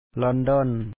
lɔ̀ɔndɔɔn London